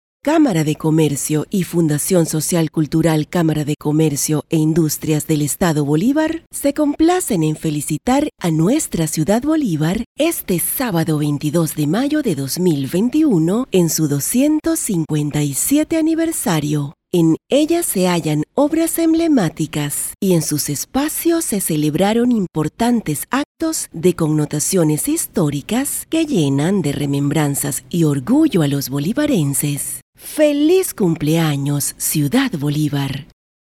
Female
Spanish (Latin American), Spanish (Venezuela)
Yng Adult (18-29), Adult (30-50)
Institutional, inspirational, compelling, educated, smooth, formal.
Studio Quality Sample